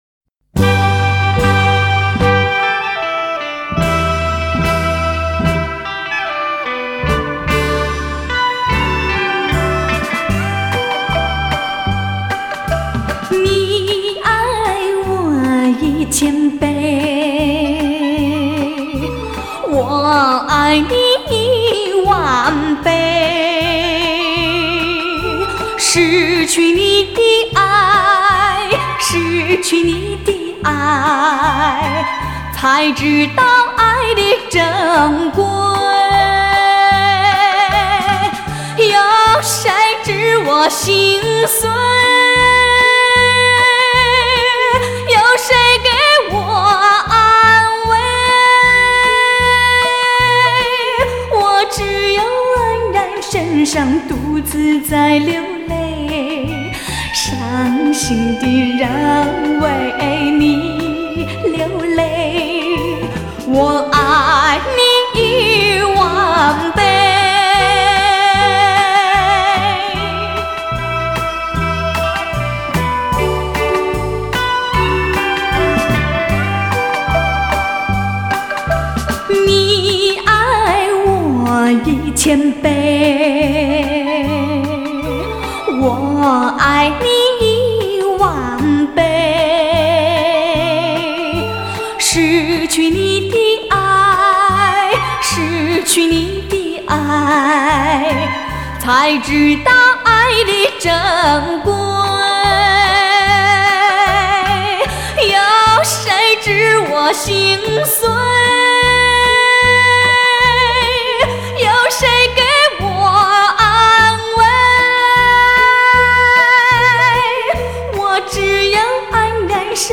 低品质